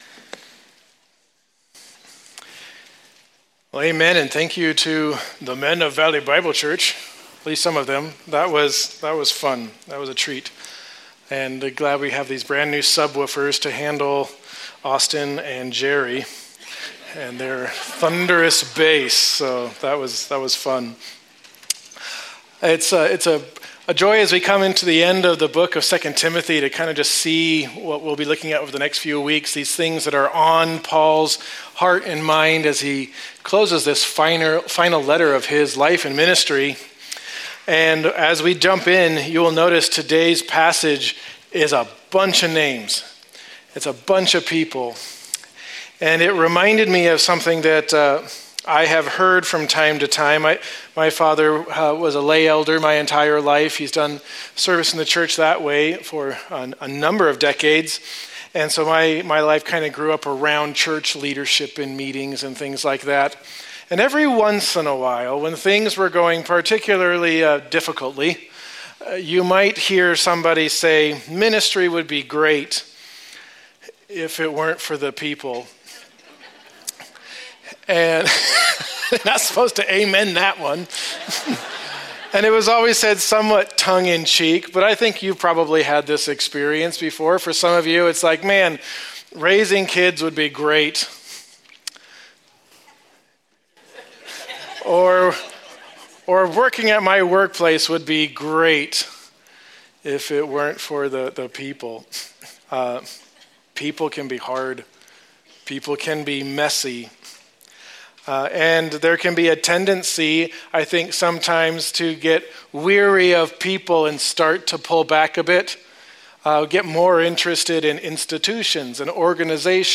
January 11’s Sunday service livestream, bulletin/sermon notes/Life Group questions, the online Connection Card, and playlists of Sunday’s music (Spotify and YouTube).